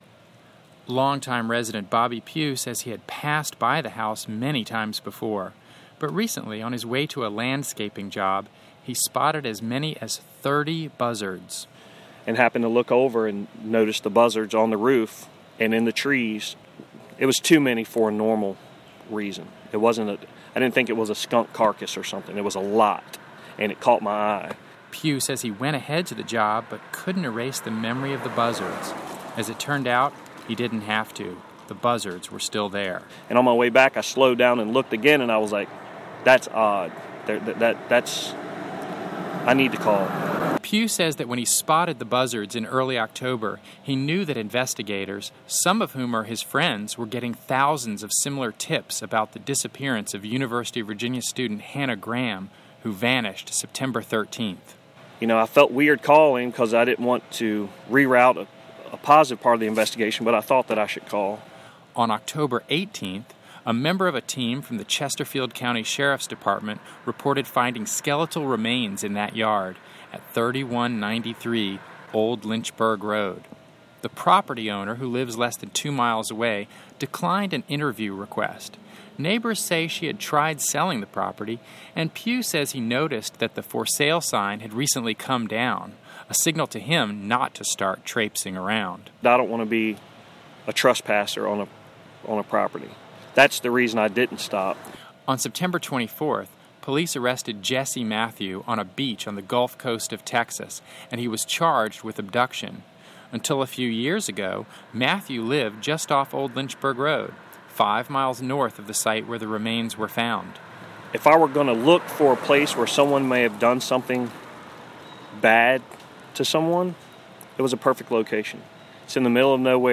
This is an audio report made for public radio station WVTF.